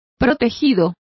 Complete with pronunciation of the translation of protege.